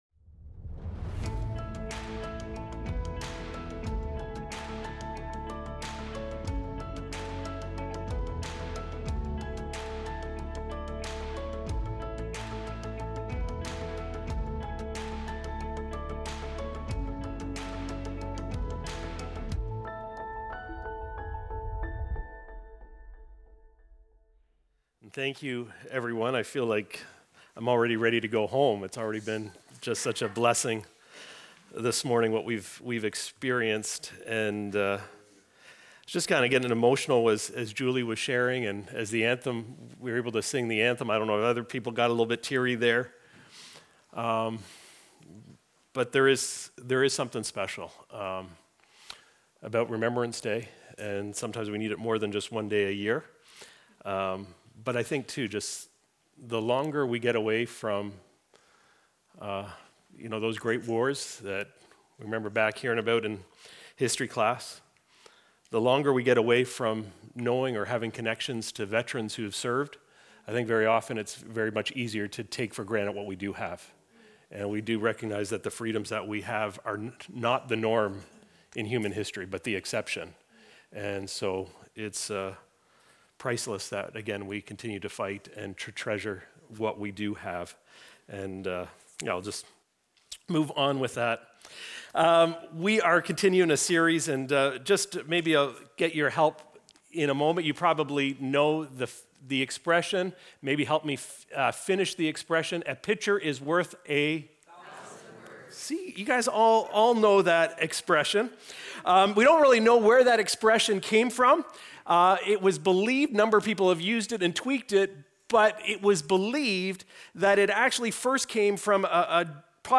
Recorded Sunday, November 9, 2025, at Trentside Fenelon Falls.